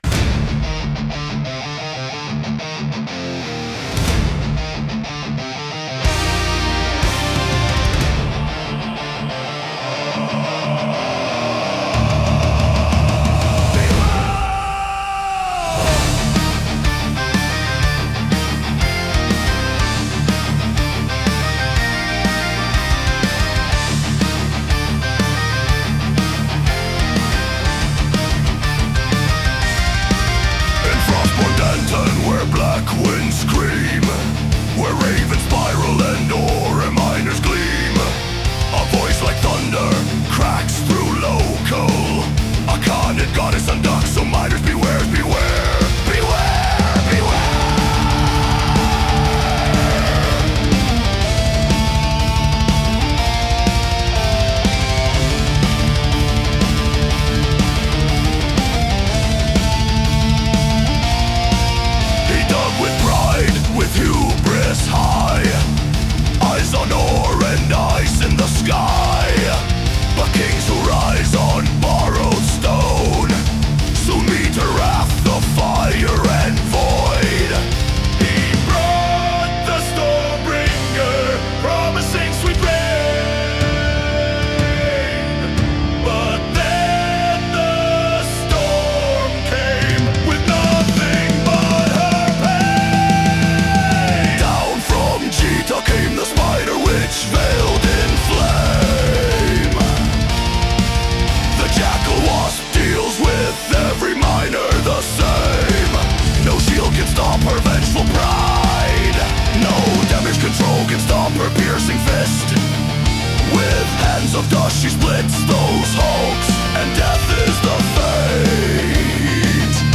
requesting I rewrite his song as a traditional Nordic battlesong.